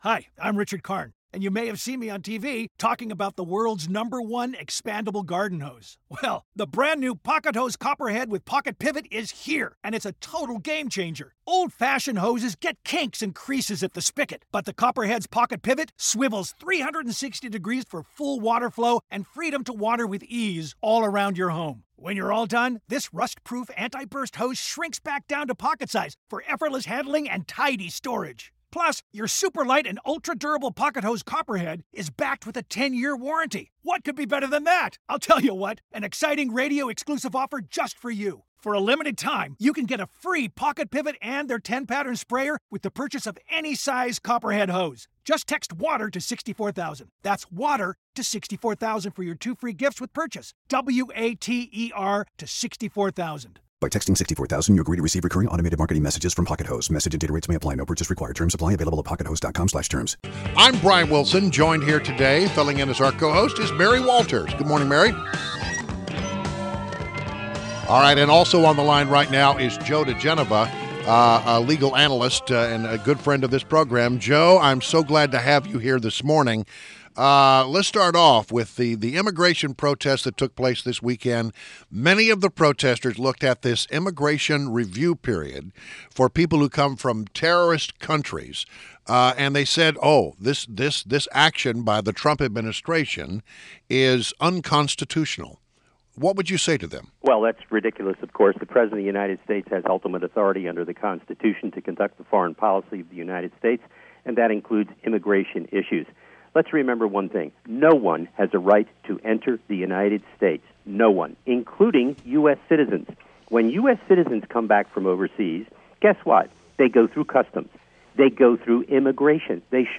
WMAL Interview - JOE DIGENOVA - 01.30.17